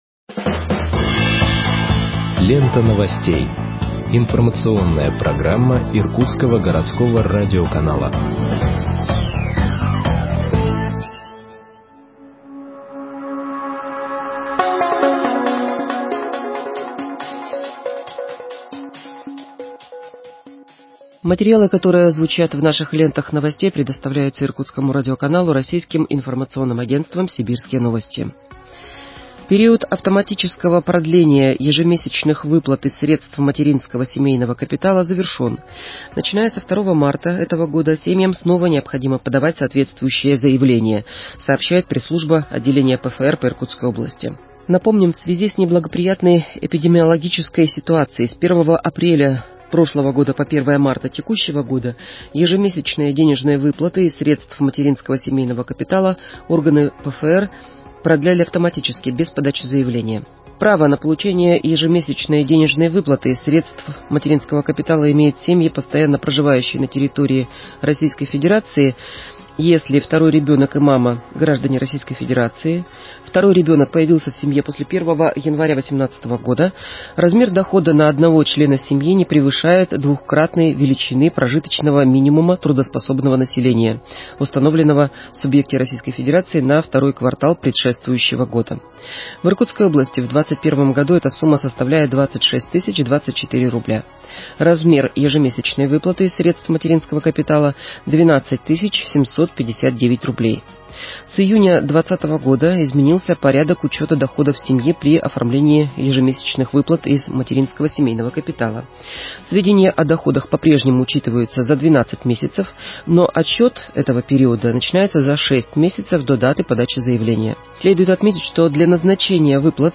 Выпуск новостей в подкастах газеты Иркутск от 25.03.2021 № 1